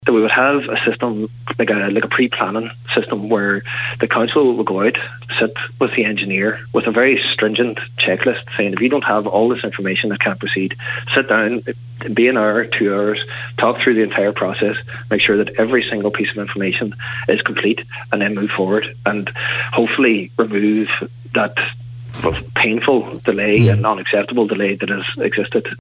Cathaoirleach of Donegal County Council Cllr Jack Murray says a pre planning system needs to be put in place to speed up the process: